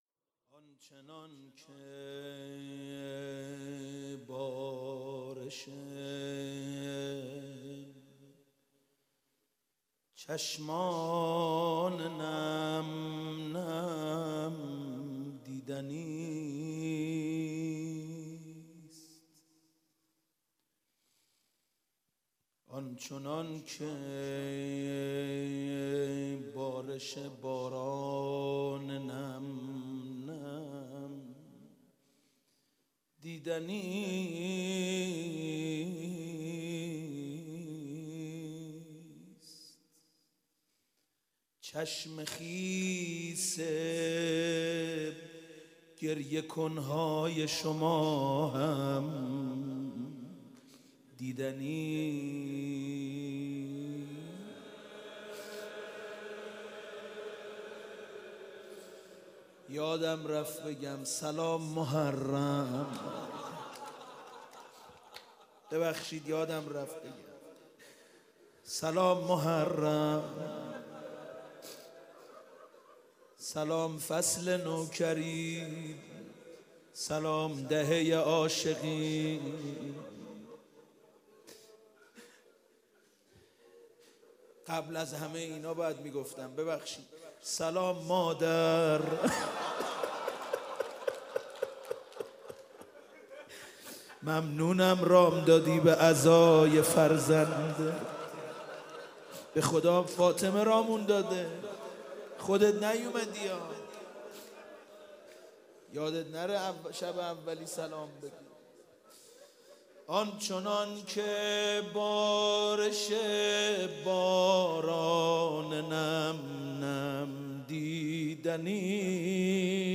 شب اول محرم 95_مناجات_آنچنان که بارش چشمان نم نم دیدنیست